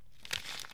SFX_papel.wav